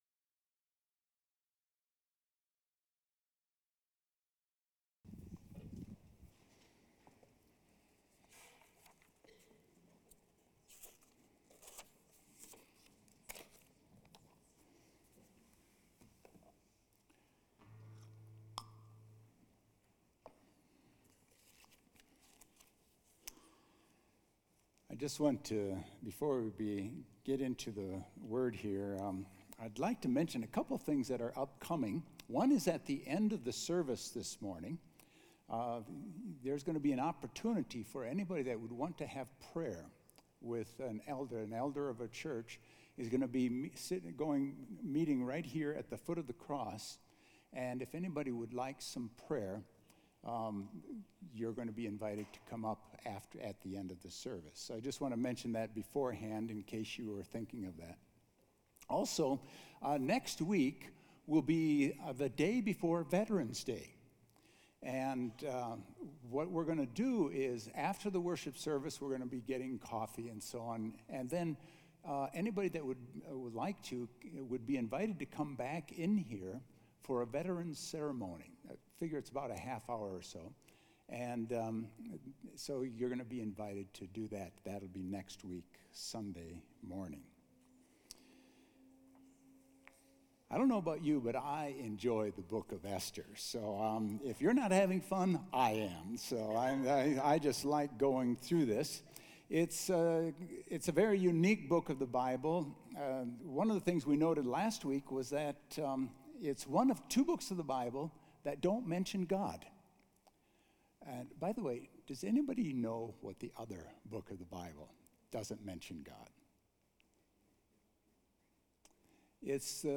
A message from the series "Esther."